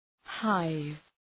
Προφορά
{haıv}